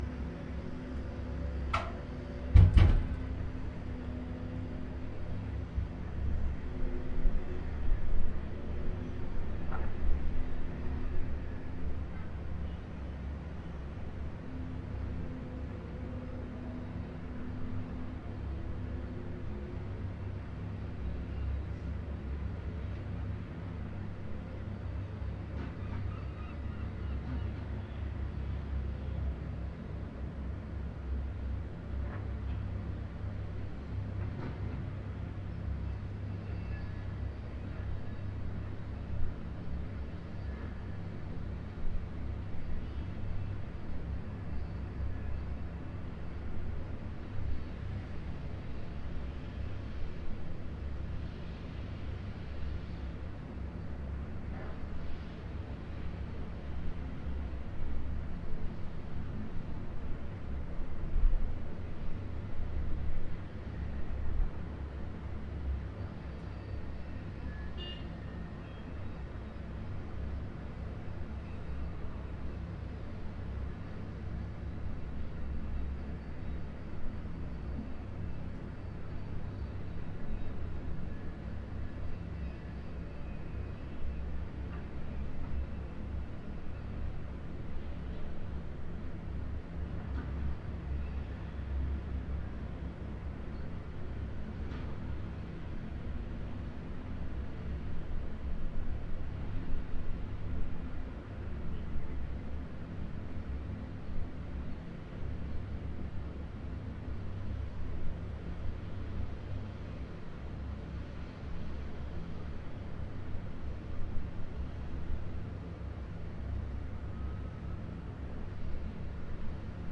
描述：2009年4月14日13时至13时30分，在荷兰鹿特丹进行了长时间的现场录音。在85米的高空记录下城市的声音。你可以听到汽车行驶的声音和正在进行的施工作业（道路维修）。一些响亮的汽车，一架直升机从录音机附近经过。索尼PCMD50
Tag: 大气 背景 建筑 汽车 城市 城市景观 建筑 距离远 现场录音 荷兰 鹿特丹 街道 街道生活 城市 工作 院子里